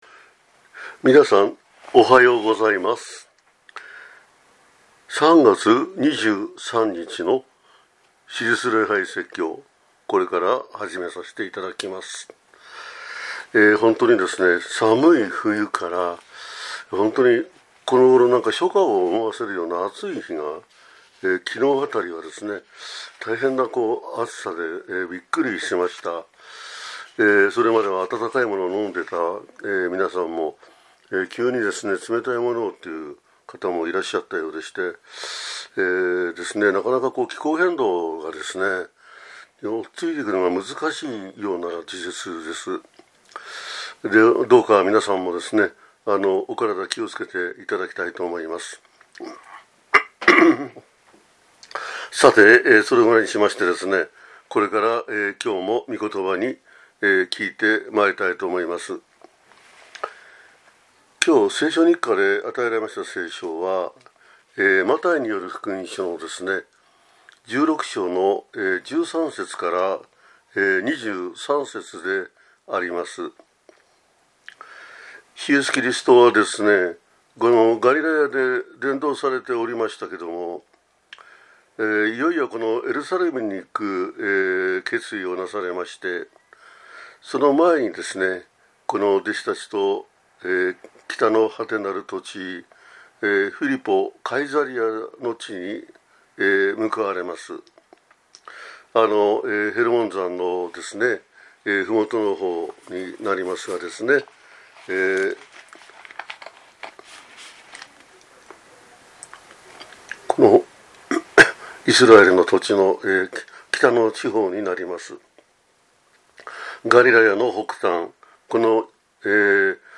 2025年3月23日（受難節第3主日）